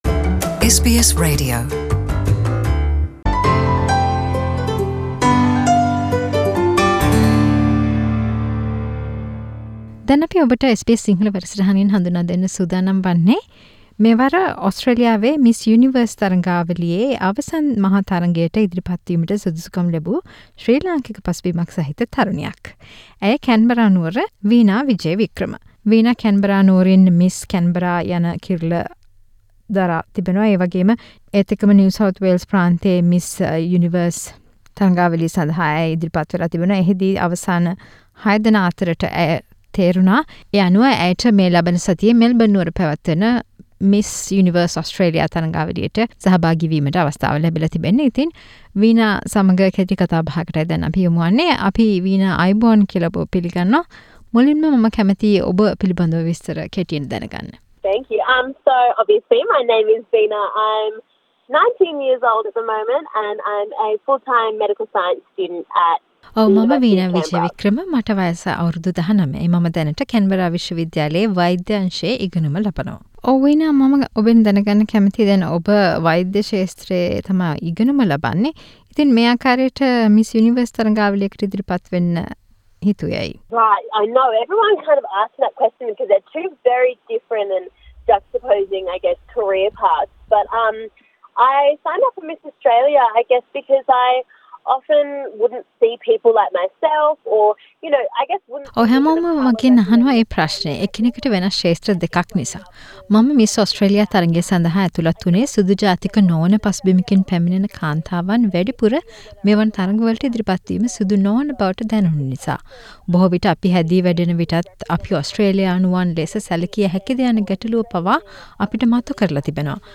SBS සිංහල සිදු කල පිළිසදර.